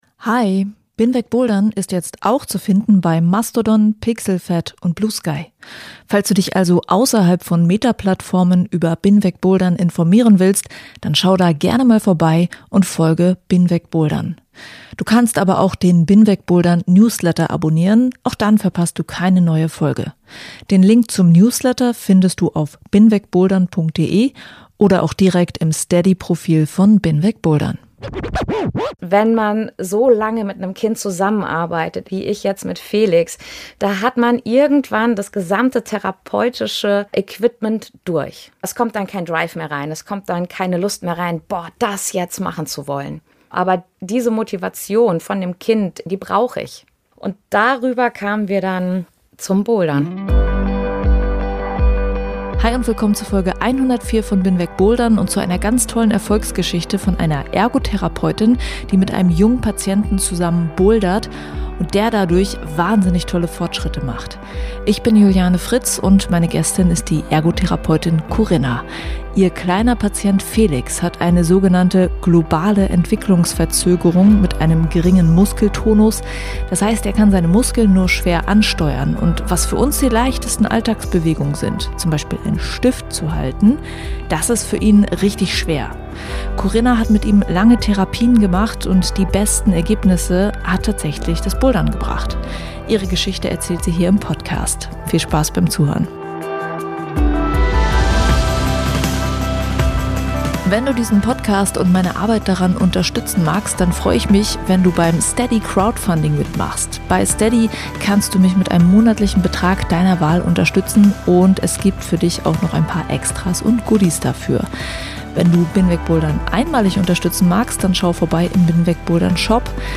Im Gespräch mit Therapeutin